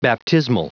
Prononciation du mot baptismal en anglais (fichier audio)
baptismal.wav